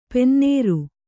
♪ pennēru